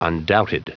Prononciation du mot undoubted en anglais (fichier audio)
Prononciation du mot : undoubted
undoubted.wav